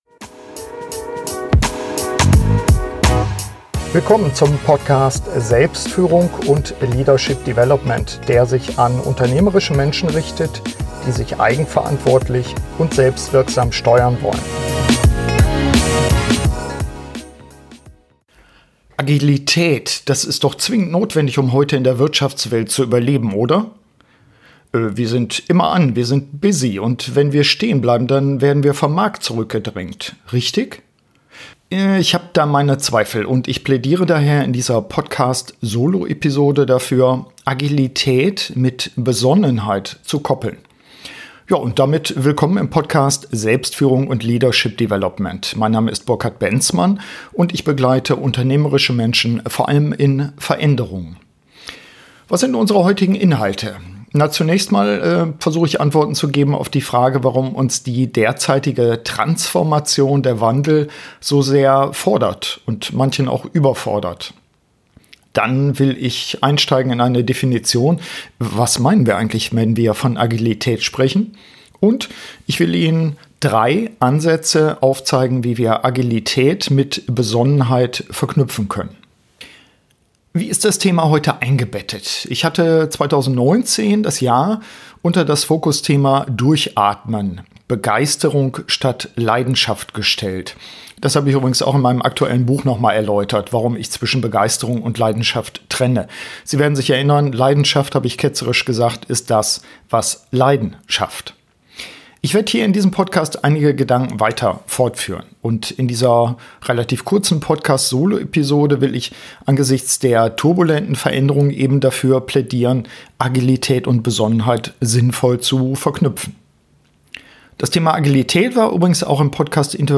In dieser Podcast-Soloepisode gebe ich drei Impulse, wie wir Agilität mit Besonnenheit verknüpfen können.